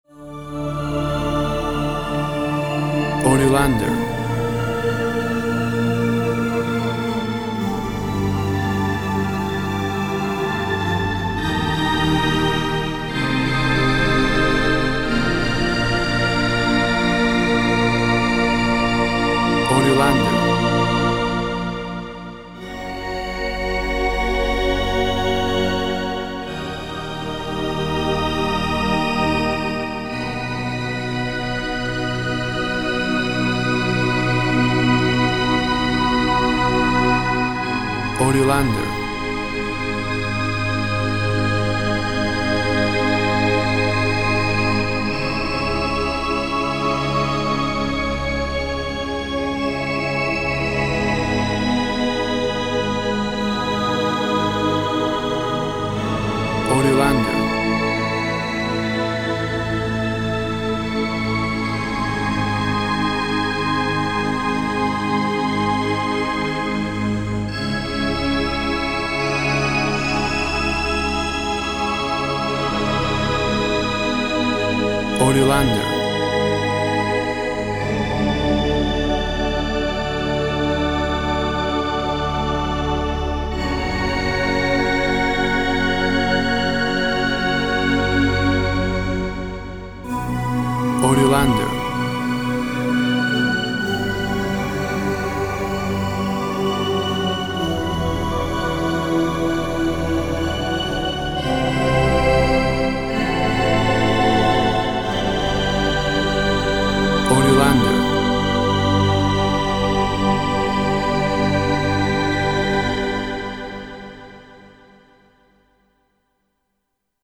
Fiery, agitated cathedral organ.
Tempo (BPM) 128